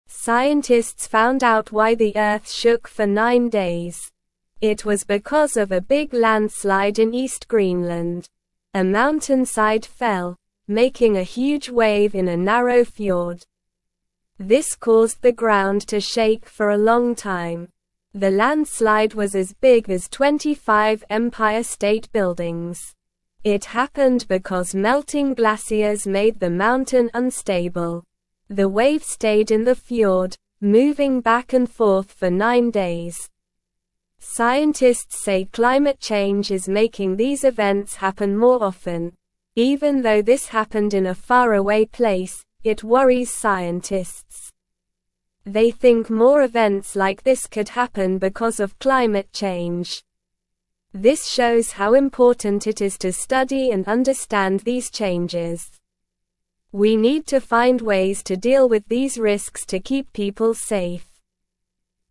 Slow
English-Newsroom-Lower-Intermediate-SLOW-Reading-Big-Wave-Shook-Earth-for-Nine-Days-Straight.mp3